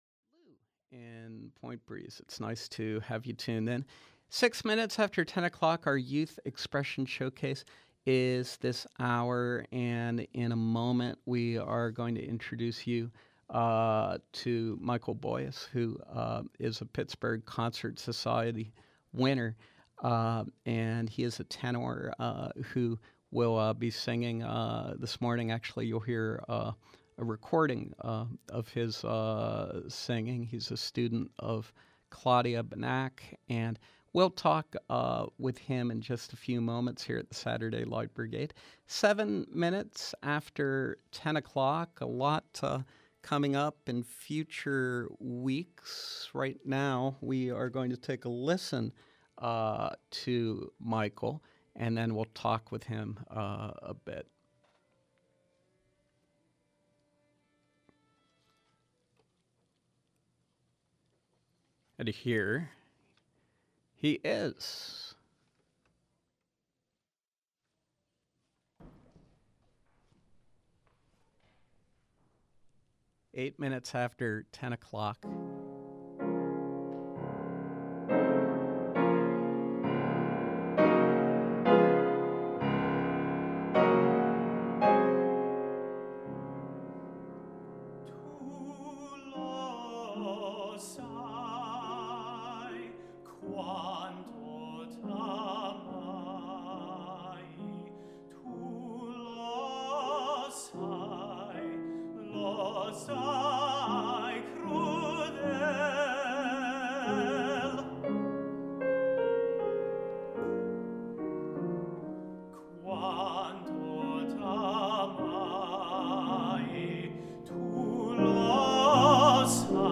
The Pittsburgh Concert Society’s Young Artists Competition provides a recital opportunity and support to talented classical musicians.
tenor, sharing with us recorded performances from his March recital.